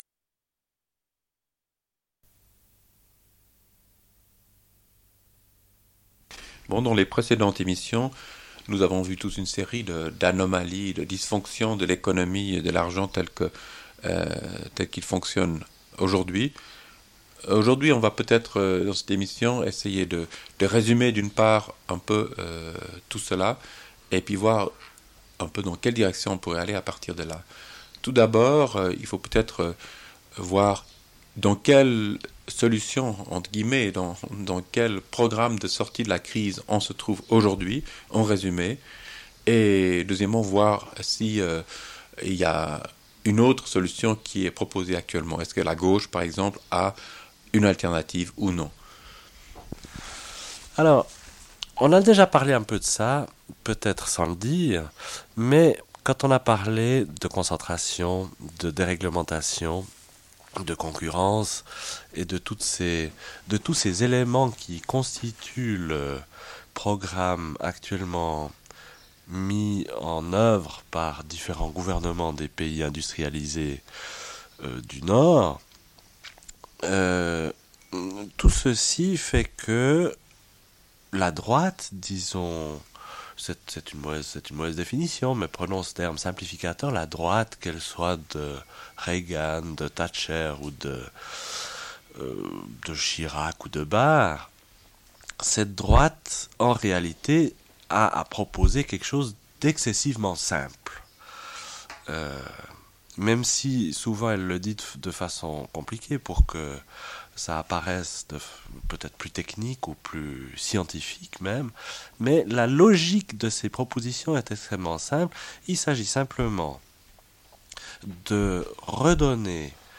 Une cassette audio